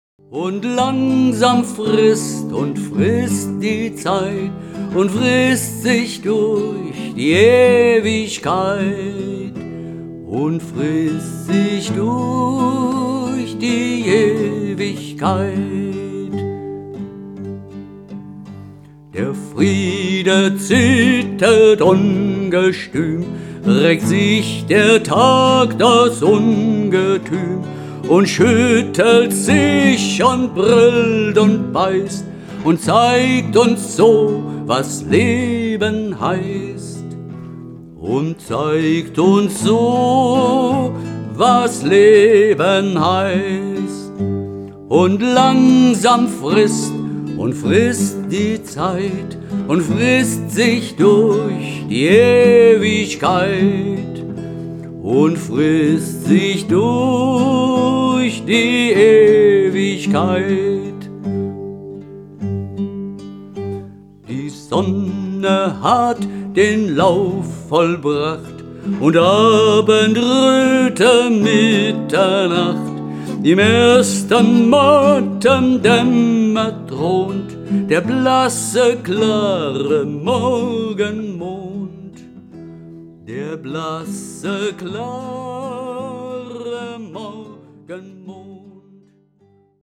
We-CD-16 - 2015 : Werkstatt XVI ( Titel 13 ) - 3/8 Takt
Die Hörprobe stammt von einer Studioaufnahme aus dem Jahr 2015.
In diesem kommt die Melodie noch stärker zur Geltung.